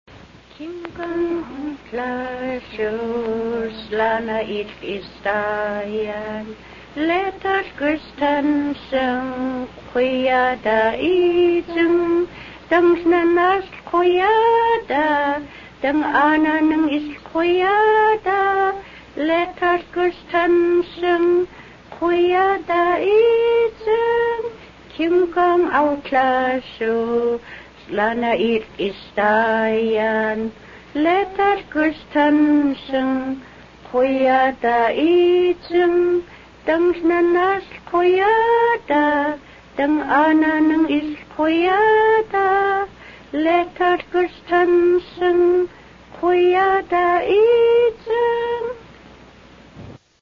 Here are six short gospel songs sung in Haida.
gospel-song-04.mp3